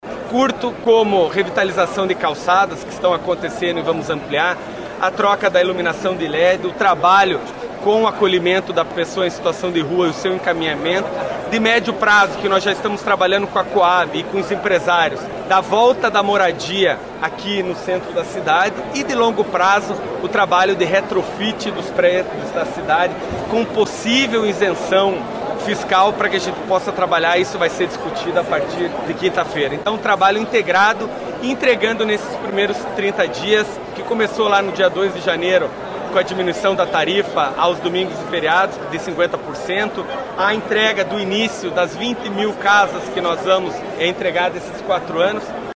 Durante uma coletiva de imprensa realizada nesta sexta-feira (31), na Rua da Cidadania Matriz, na Praça Rui Barbosa, Pimentel disse que na próxima quinta-feira, dia 6 de fevereiro, vai lançar uma comissão para discutir sobre o assunto.